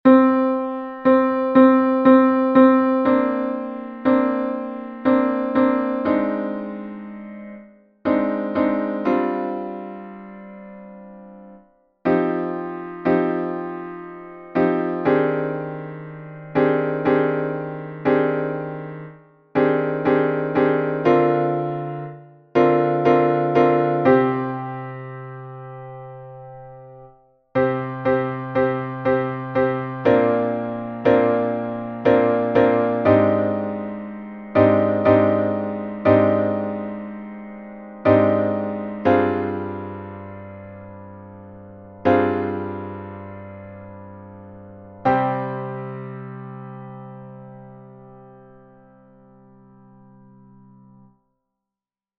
for us to make practice tracks.)
Turn down the volume. These are loud.
Tutti
The featured voice is a bassoon or a horn;
other voices sound like a piano.